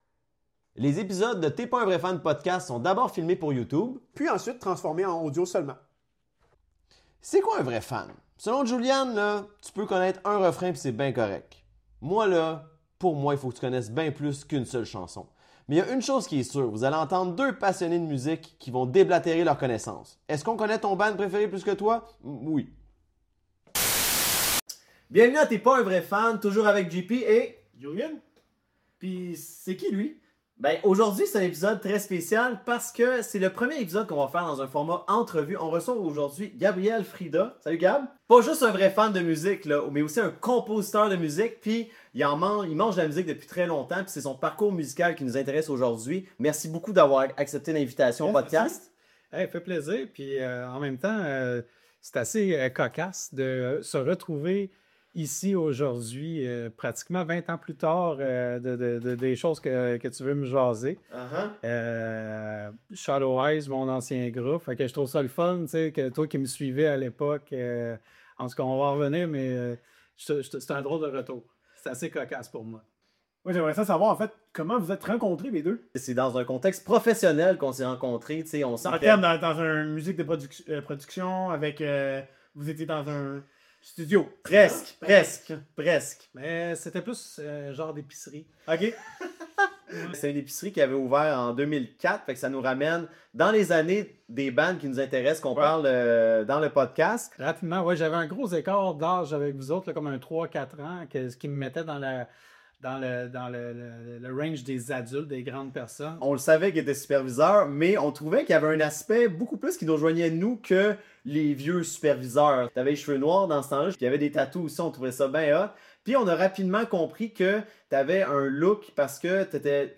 Suivi d’une prestation acoustique.